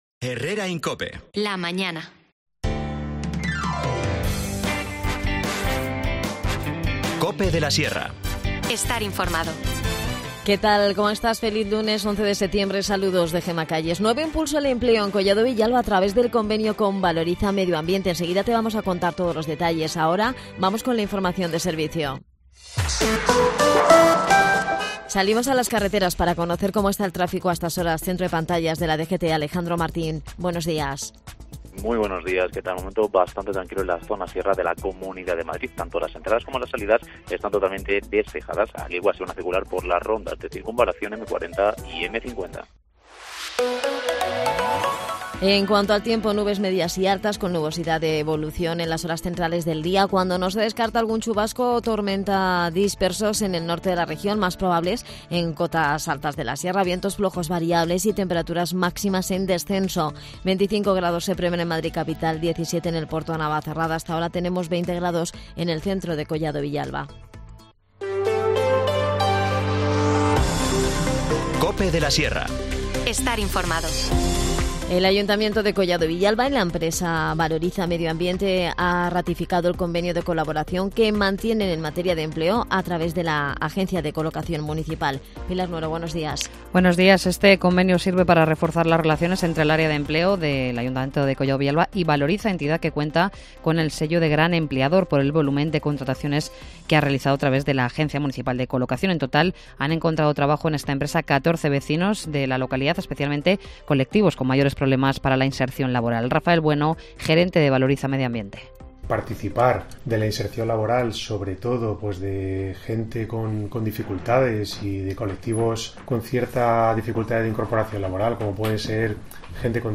Pero hay más iniciativas que nos ha adelantado José María Escudero, concejal de Inclusión y Accesibilidad.